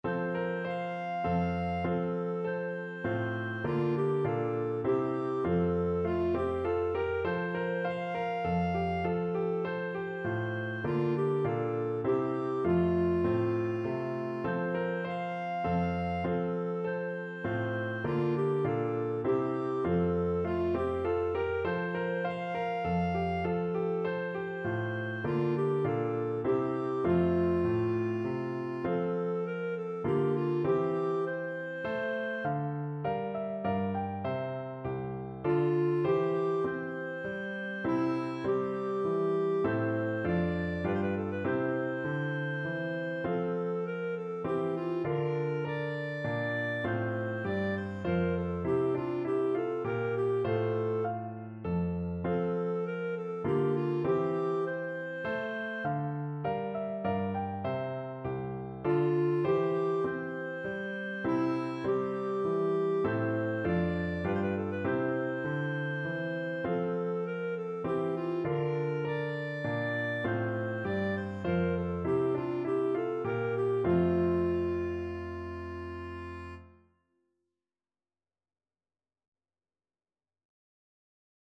Clarinet
F major (Sounding Pitch) G major (Clarinet in Bb) (View more F major Music for Clarinet )
3/4 (View more 3/4 Music)
Moderato = c.100
Classical (View more Classical Clarinet Music)